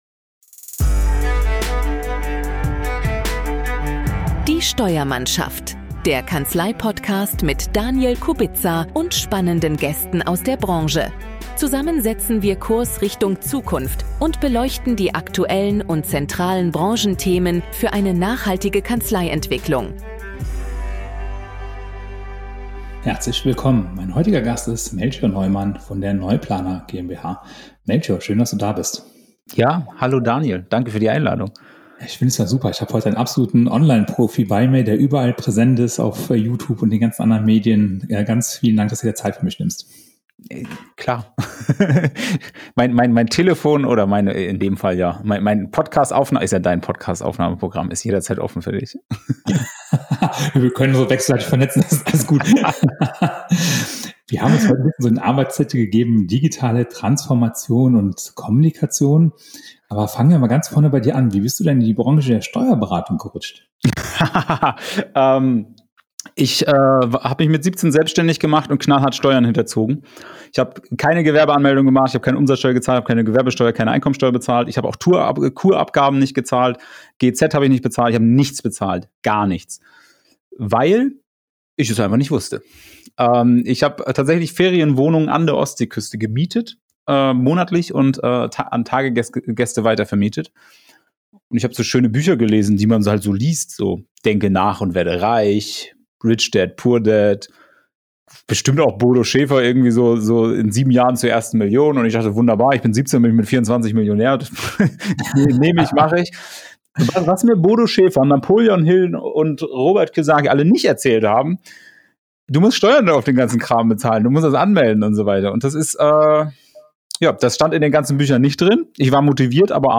STUDIOGAST